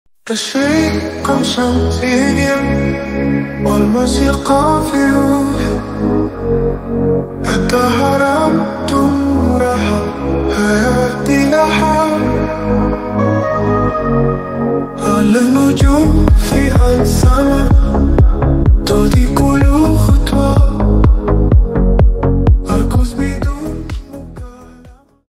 Style : Eurodance